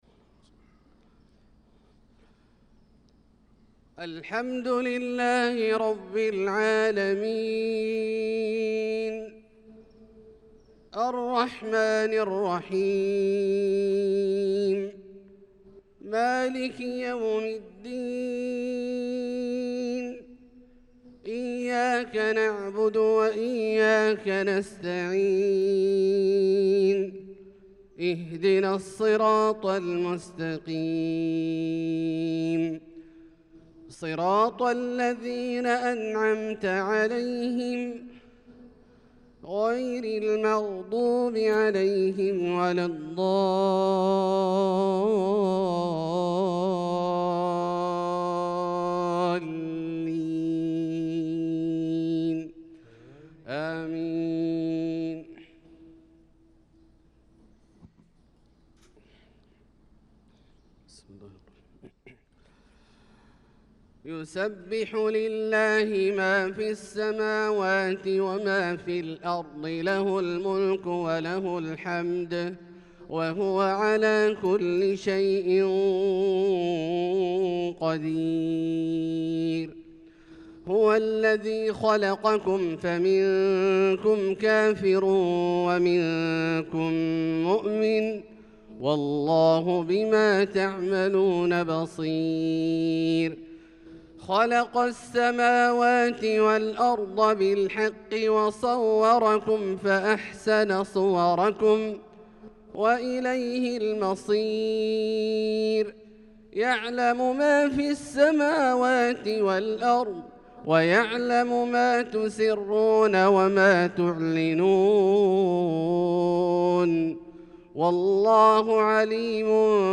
صلاة الفجر للقارئ عبدالله الجهني 13 شوال 1445 هـ